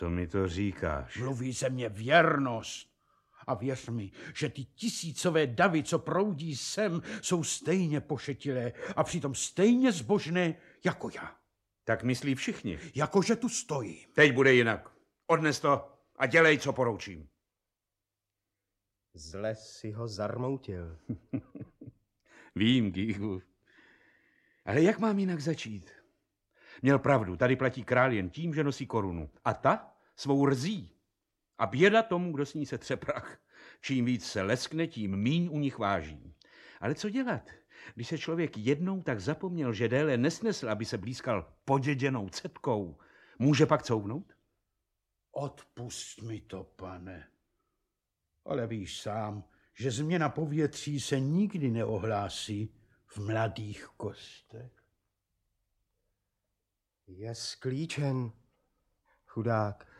Audiobook
Audiobooks » Short Stories, Classic Works